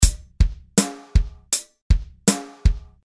标签： 80 bpm Acoustic Loops Drum Loops 516.99 KB wav Key : Unknown
声道立体声